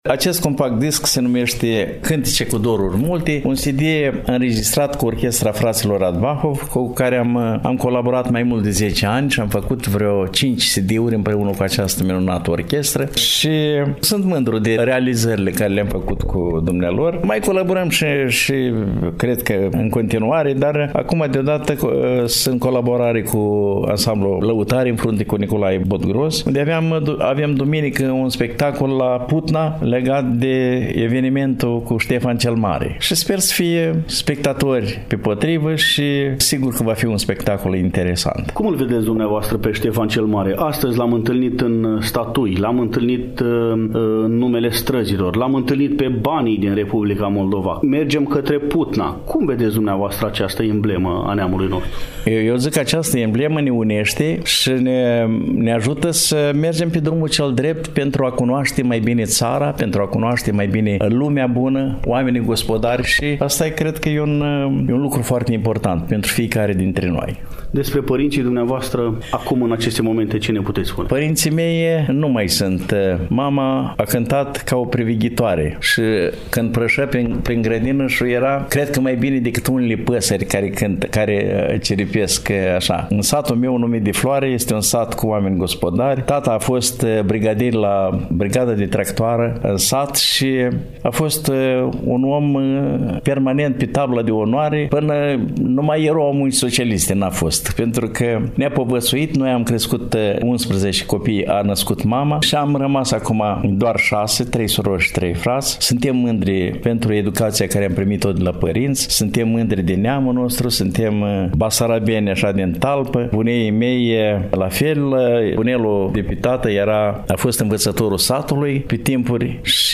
3_Dialog-Interpret-de-Muzica-Populara-Mihai-Ciobanu-2-42.mp3